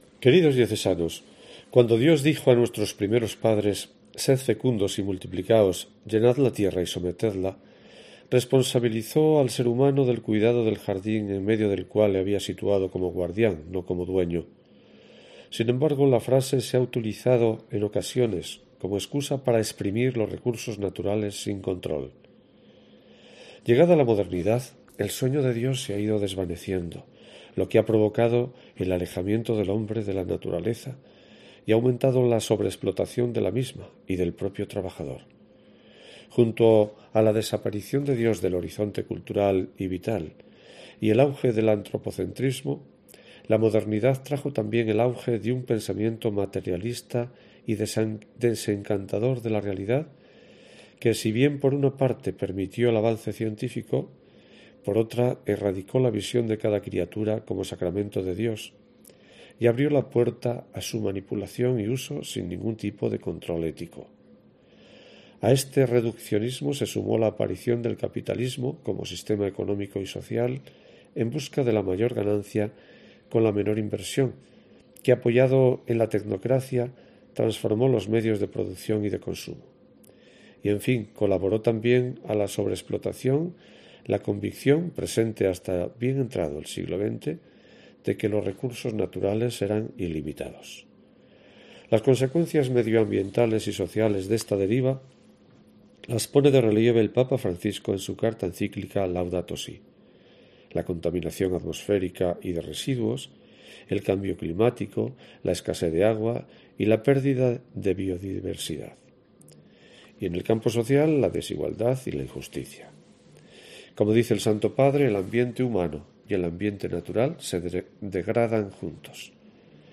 Escucha aquí la carta de esta semana del obispo de Astorga